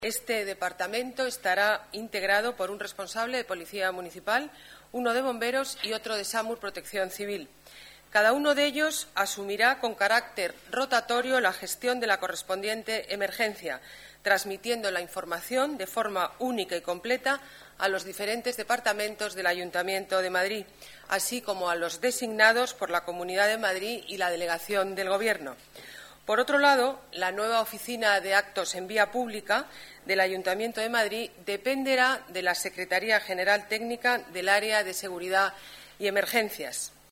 Nueva ventana:Declaraciones de la alcaldesa, Ana Botella